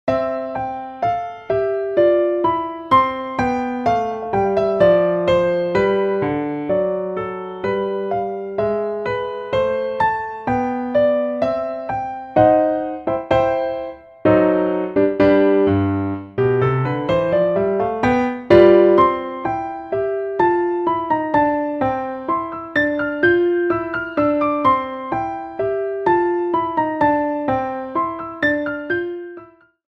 Trimmed to 30 seconds and applied fadeout
Fair use music sample